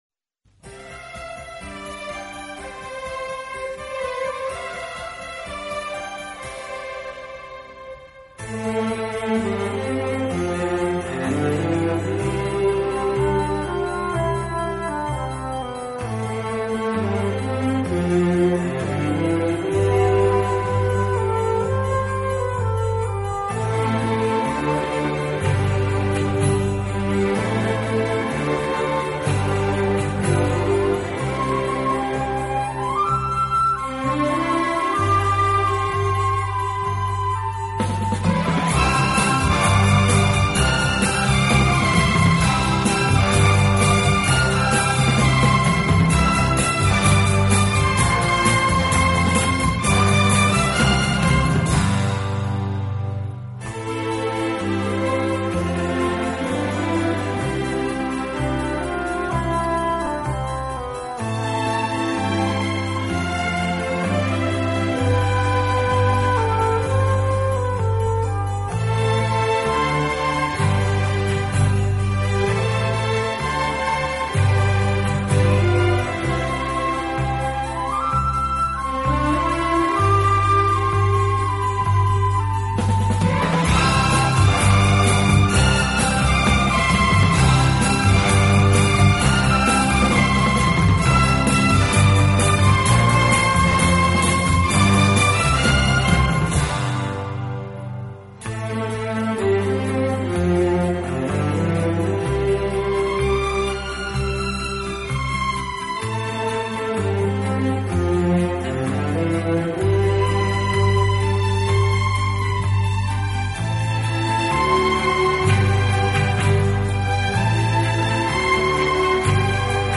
提琴”弦乐队。
乐队以弦乐为中坚，演奏时音乐的处理细腻流畅，恰似一叶轻舟，随波荡